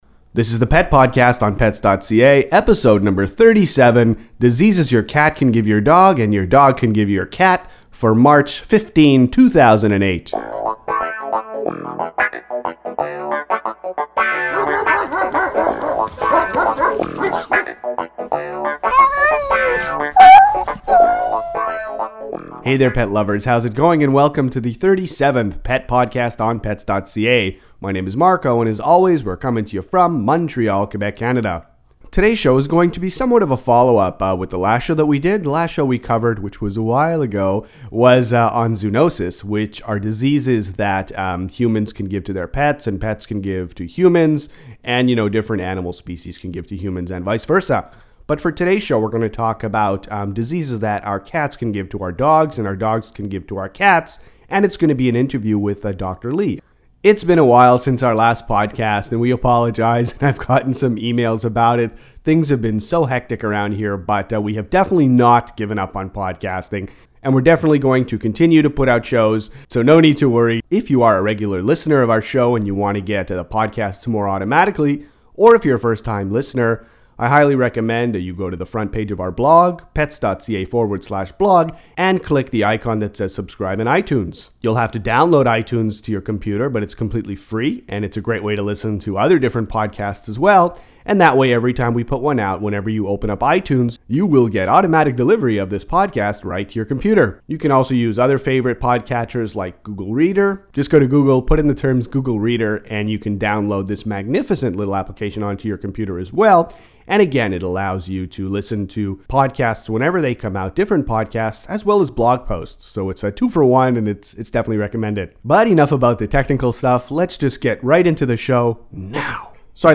Dog to cat diseases – cat to dog diseases – Pet podcast #37 – Interview